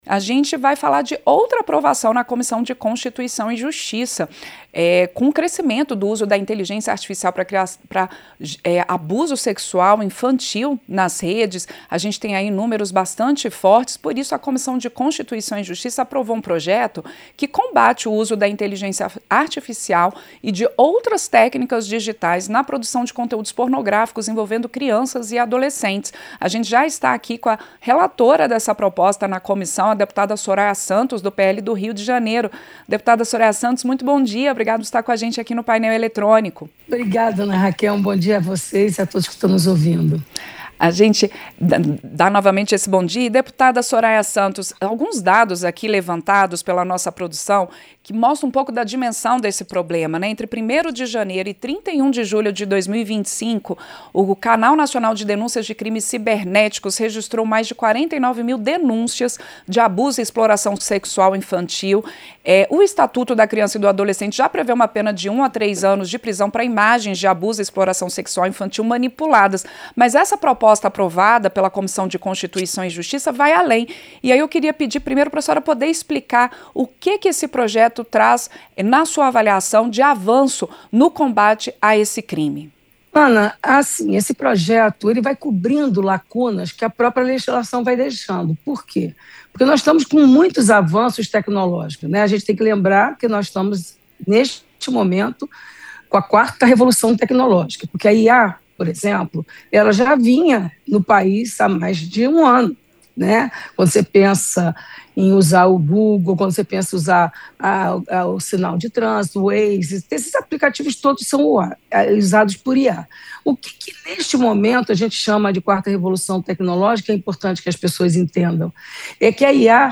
Entrevista -Dep. Soraya Santos (PL-RJ)